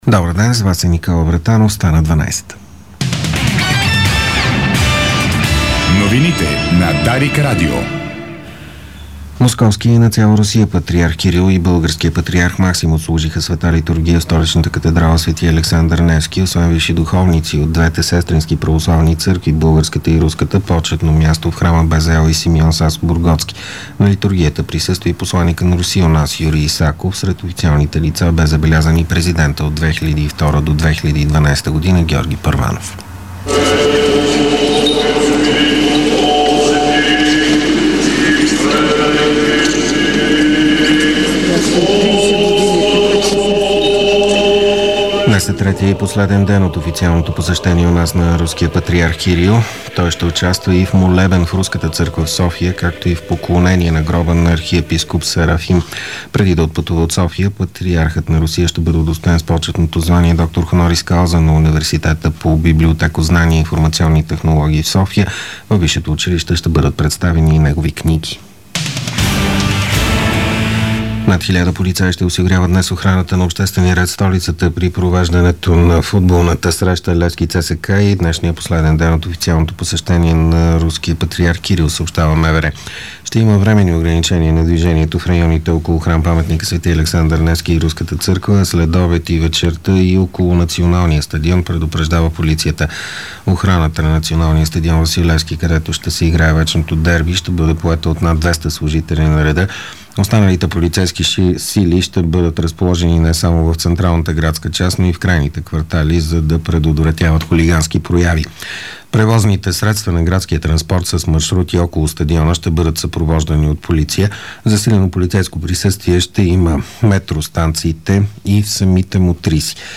Обедна информационна емисия - 29.04.2012